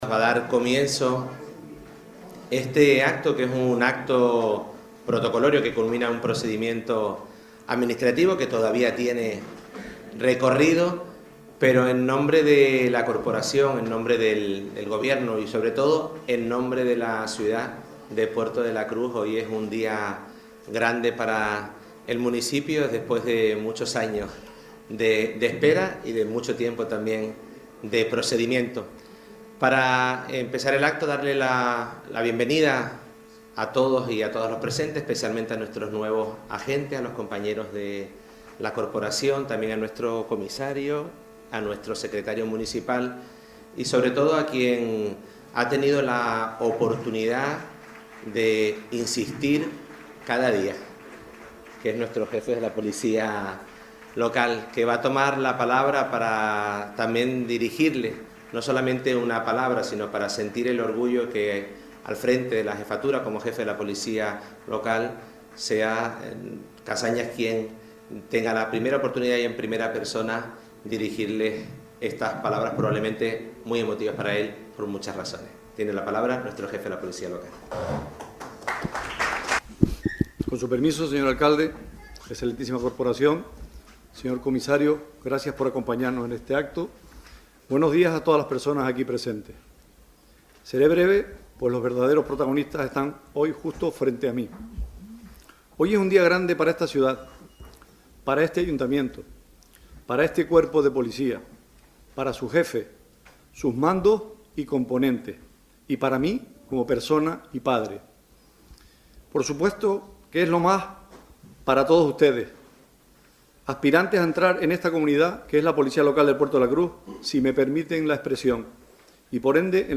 Puerto de la Cruz. Acto toma de posesión de los nuevos efectivos Policía Local - Gente Radio